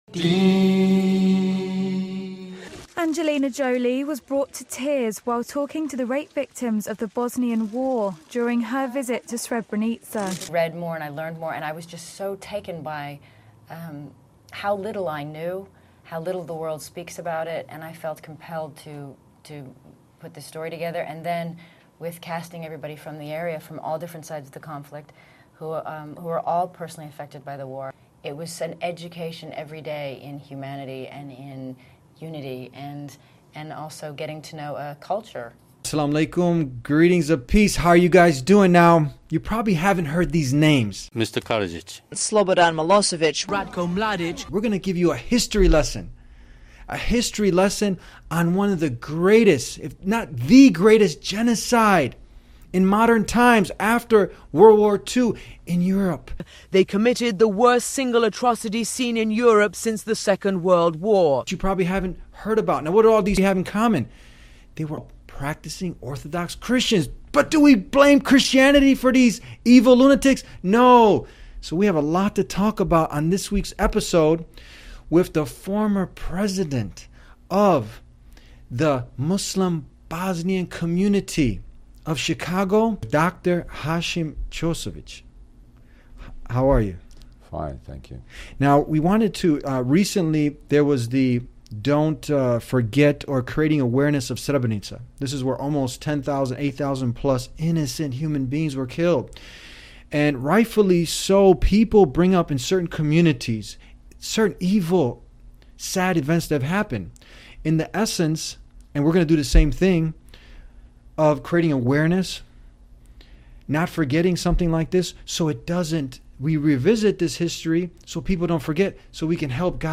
The discussion challenges misconceptions and addresses the importance of acknowledging and learning from such dark chapters in history to prevent similar tragedies in the future, promoting understanding and unity rather than perpetuating hate or biases. The guest details his involvement in providing humanitarian aid and support to Bosnian refugees during the conflict, underscoring the human impact of the genocide.